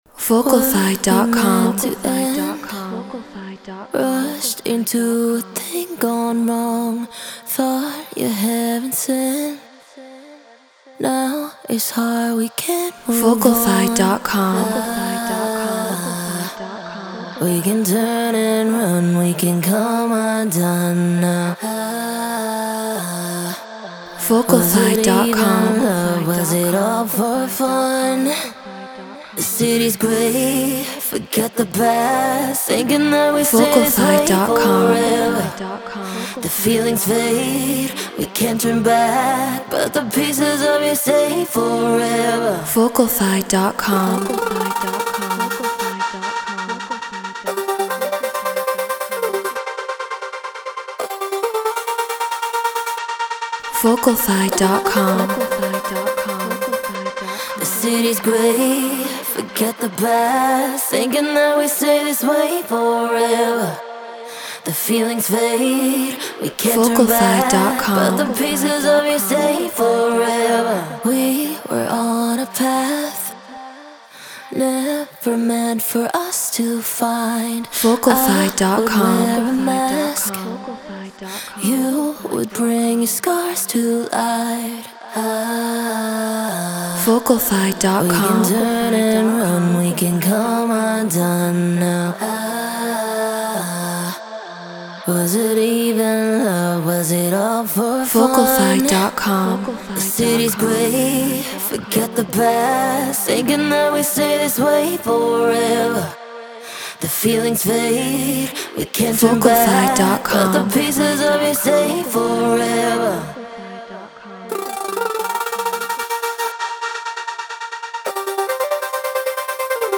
EDM 136 BPM A#maj
SE2200A MKII Presonus Studio 24 Logic Pro Treated Room